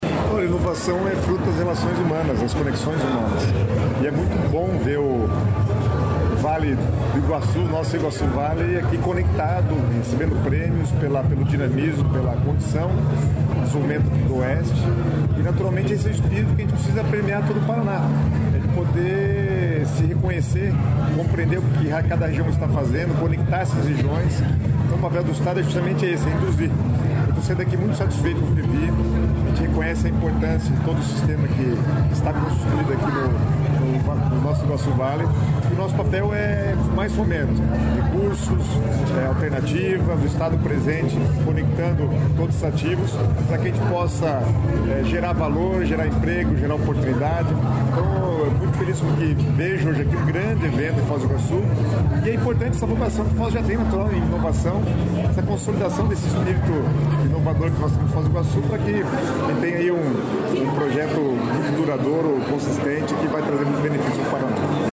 Sonora do secretário estadual do Planejamento, Guto Silva, sobre o o Summit Iguassu Valley Latinoamerica